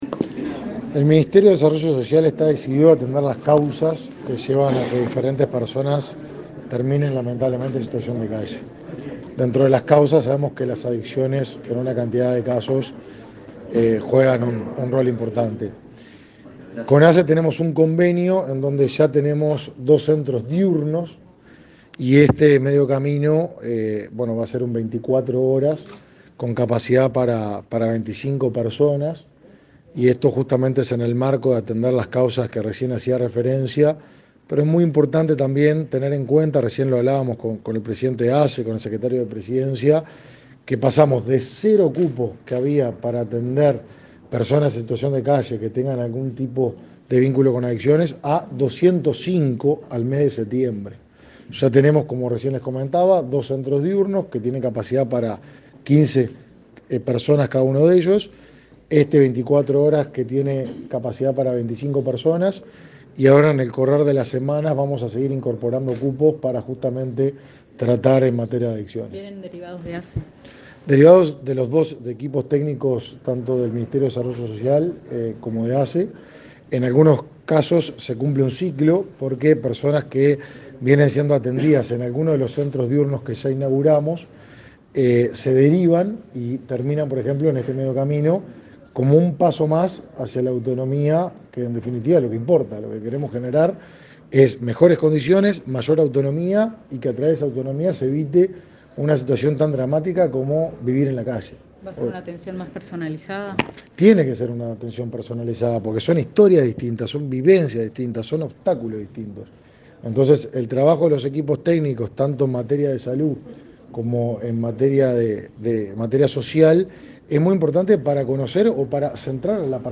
Declaraciones a la prensa del ministro de Desarrollo Social, Martín Lema